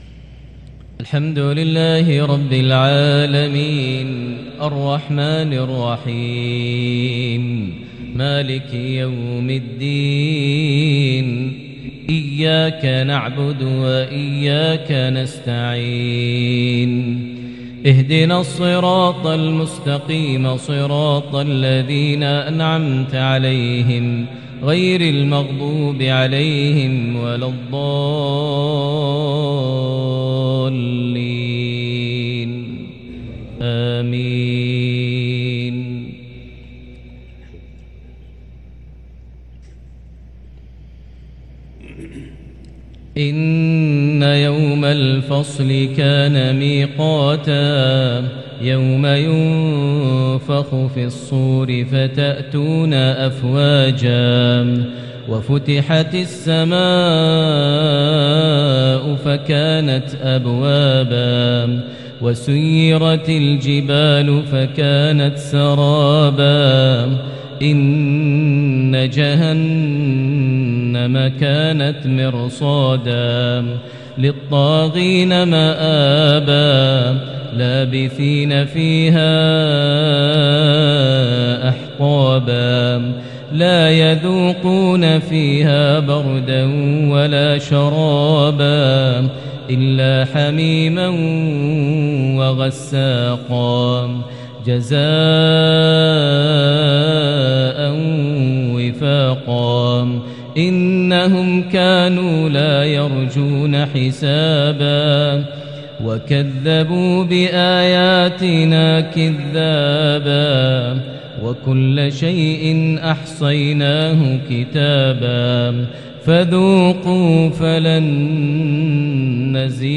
صلاة المغرب خواتيم سورة النبأ | 4 ذو القعدة 1443هـ| maghrib 3-6-2022 prayer from Surah An-Naba > 1443 🕋 > الفروض - تلاوات الحرمين